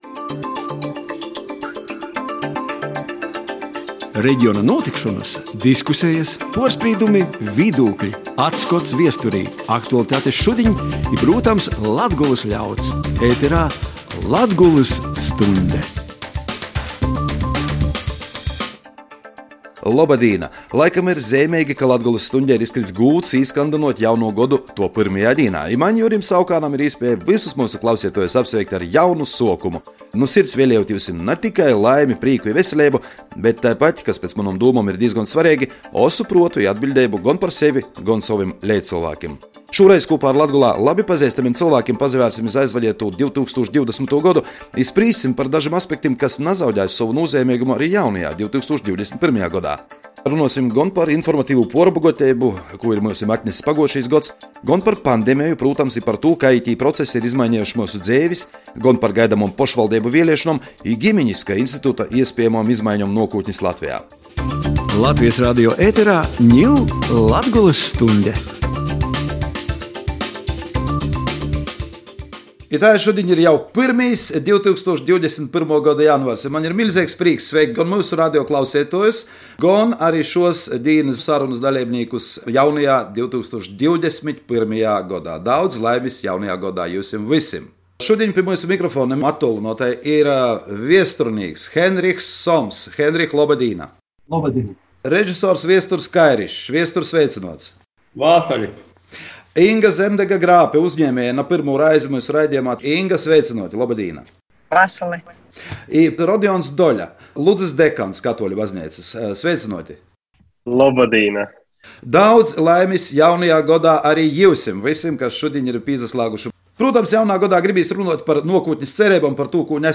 Diskuseja par tū, kū Latgolai, Latgolys dzeivuotuojim i Latvejai kūpumā atness 2020. gods, kaidus procesus aizsuocs i kai tys ītekmēs jau 2021. godu i tuoluoku nuokūtni.